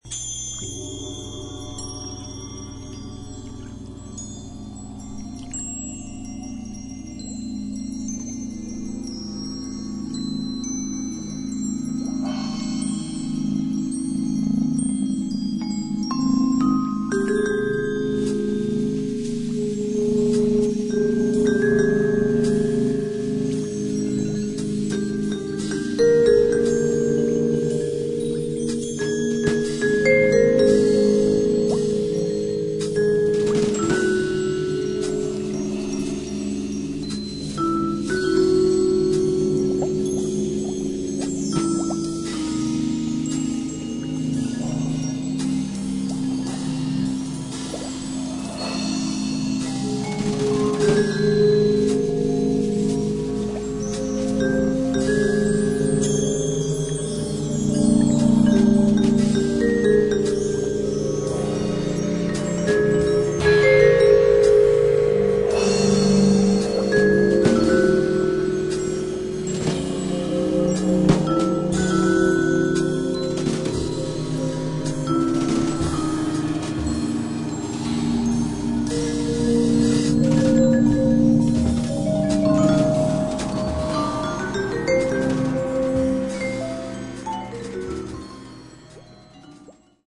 現代音楽やフリー・ジャズ、アヴァンギャルドな雰囲気も併せ持つ